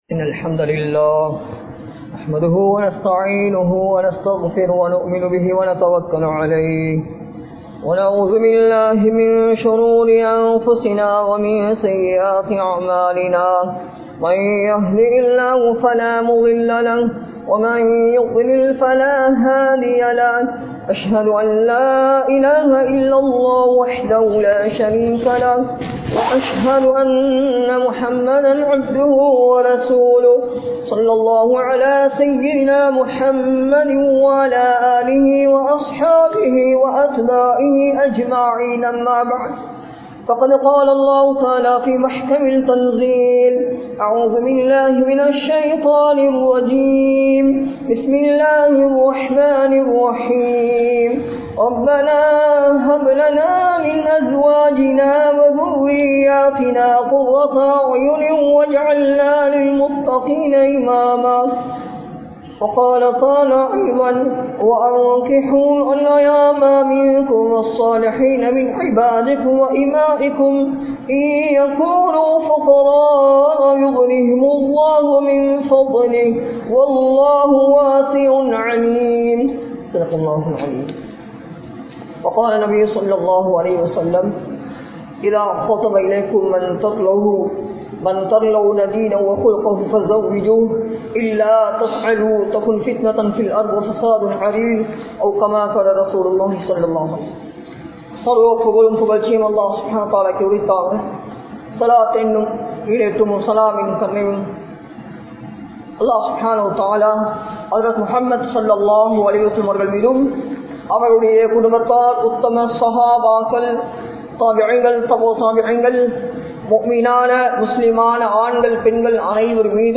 Nikkah (திருமணம்) | Audio Bayans | All Ceylon Muslim Youth Community | Addalaichenai
Masjidun Noor Jumua Masjidh